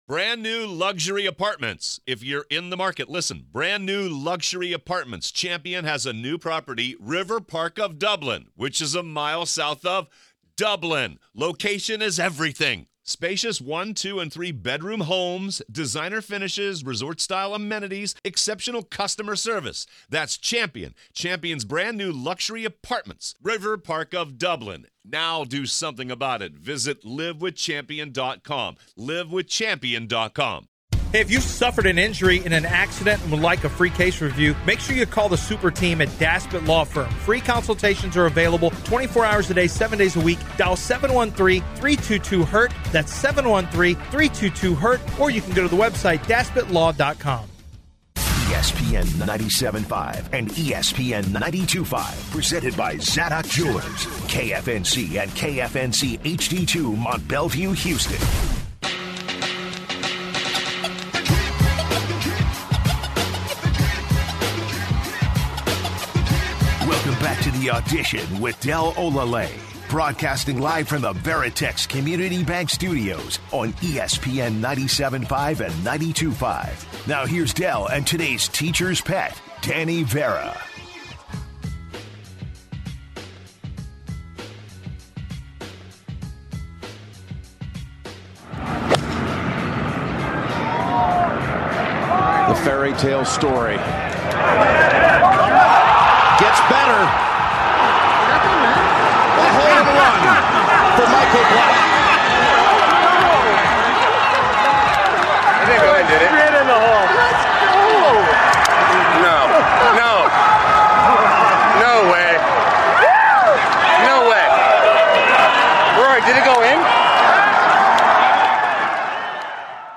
Listeners chime in to give their opinions on the matter.